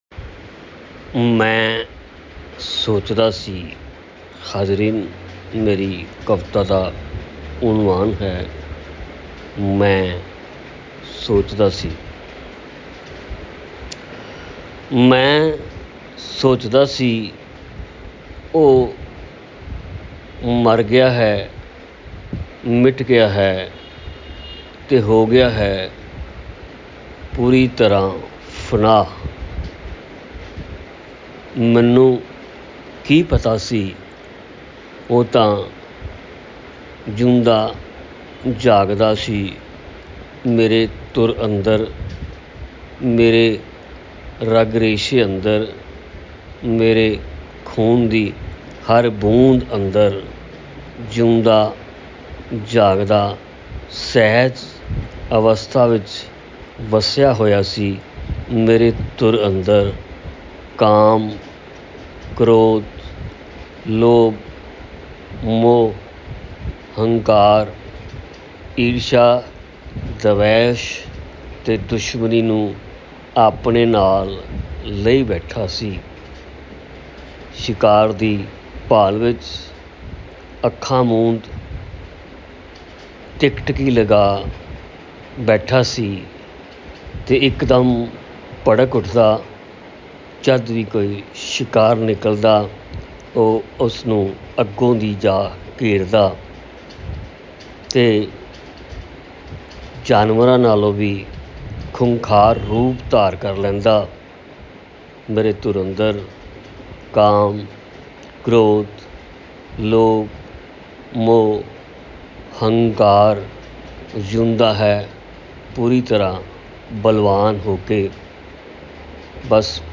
ਕਵਿਤਾ ਸੁਣੋ ਉਹਨਾ ਦੀ ਅਬਾਜ਼ ਵਿੱਚ